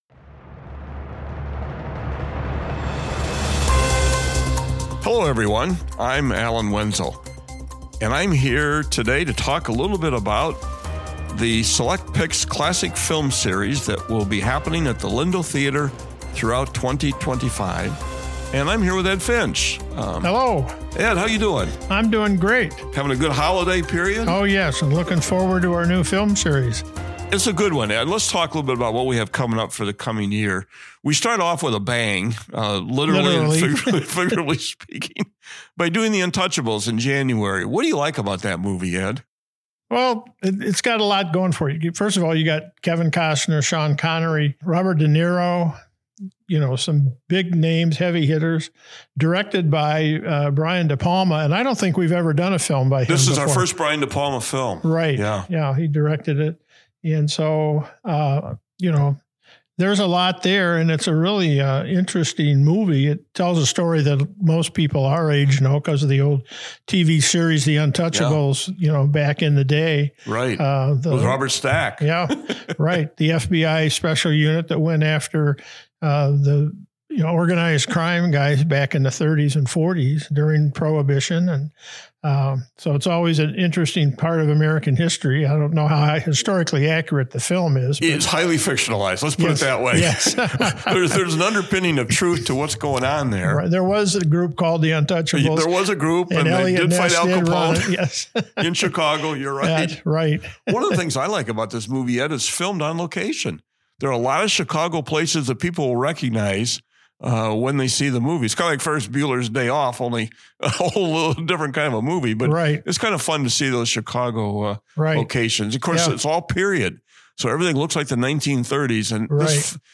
Freepod - Freepod Interviews: Select Pix Classic Film Series